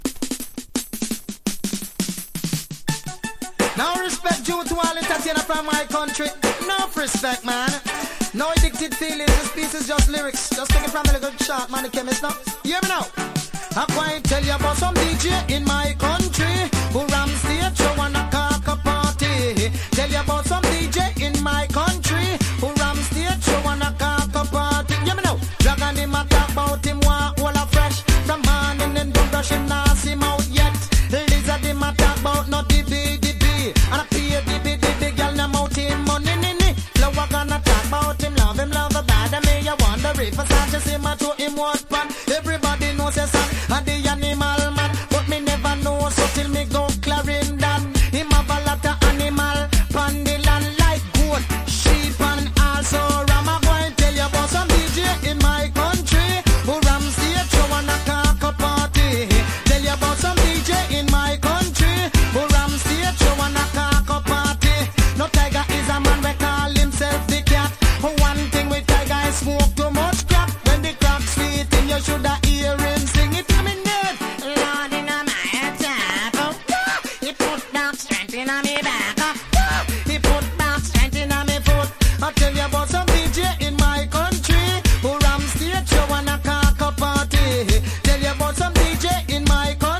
• REGGAE-SKA
DANCE HALL
所によりノイズありますが、リスニング用としては問題く、中古盤として標準的なコンディション。